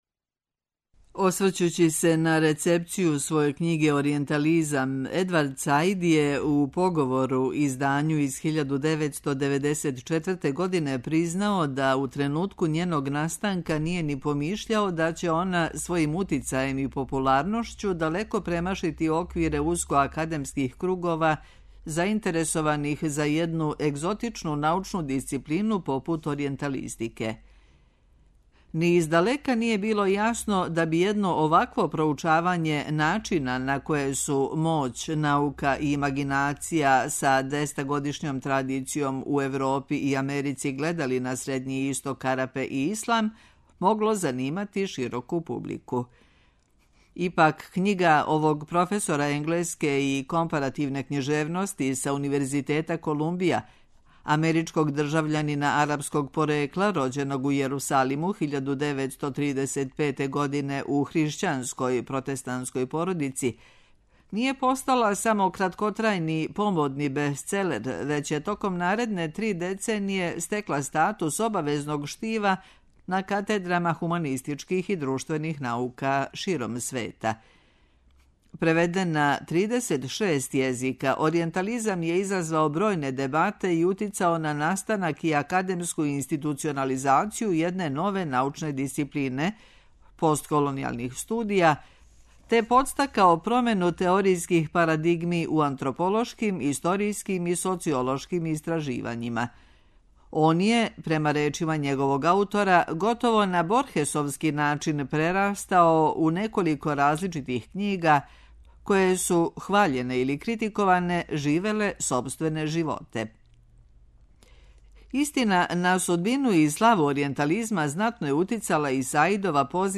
преузми : 28.40 MB Тема недеље Autor: Редакција Прва говорна емисија сваке вечери од понедељка до петка.